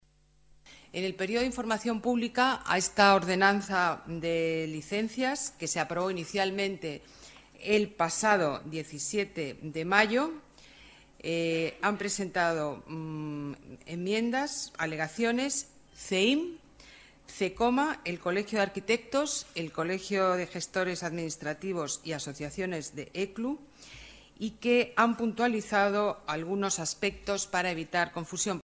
Nueva ventana:Declaraciones alcaldesa Ana Botella: Trámites más sencillos actividad económica